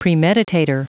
Prononciation du mot premeditator en anglais (fichier audio)
Prononciation du mot : premeditator